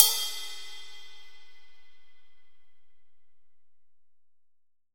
Index of /90_sSampleCDs/AKAI S6000 CD-ROM - Volume 3/Ride_Cymbal2/JAZZ_RIDE_CYMBAL